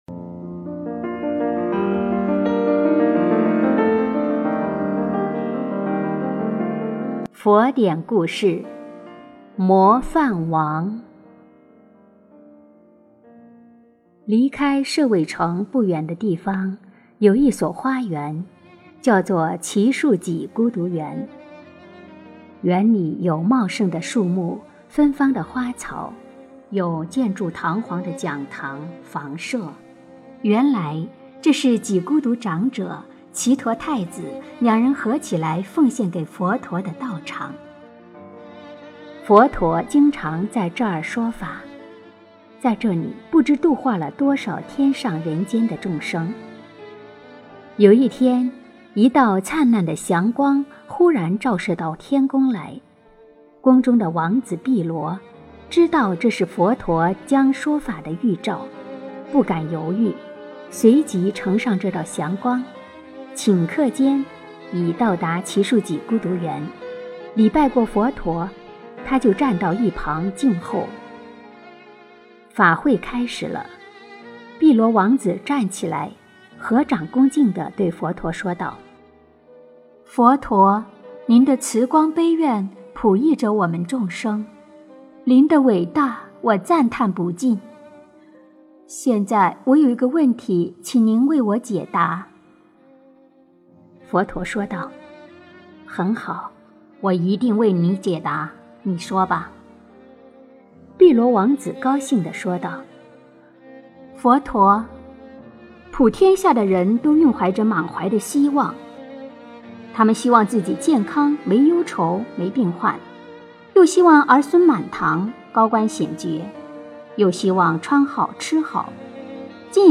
模范王 - 诵经 - 云佛论坛